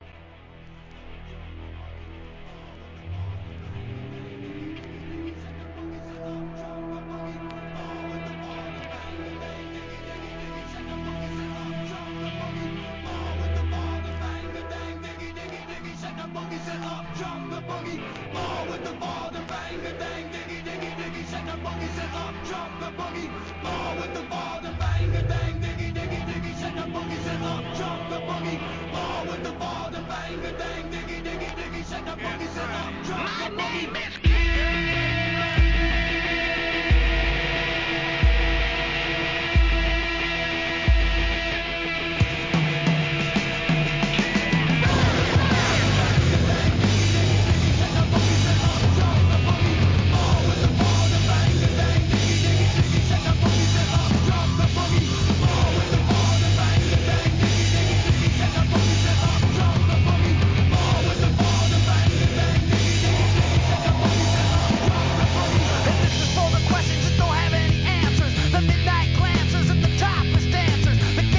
ミクスチャー